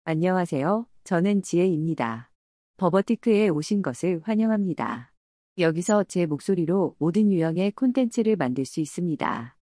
FemaleKorean
Voice sample
Female
Convert any text to natural Korean speech using Jihye's female voice.